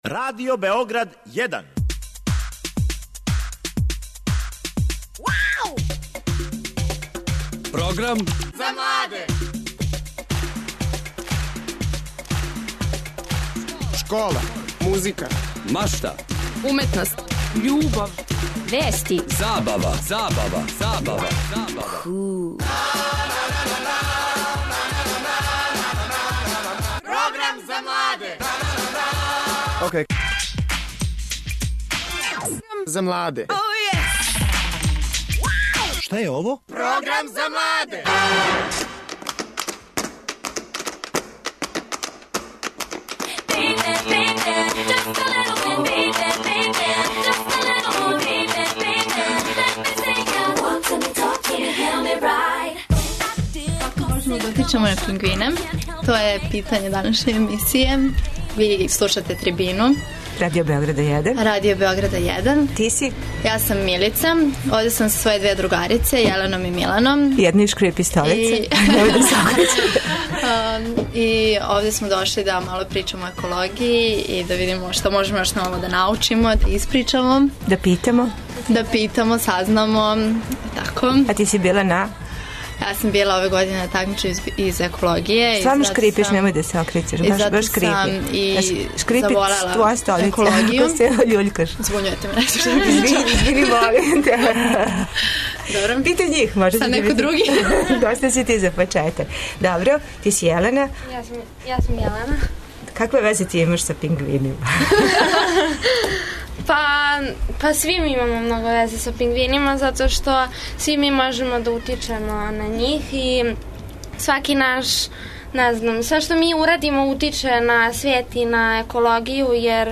Гости: деца и одрасли из еколошког друштва Биоген.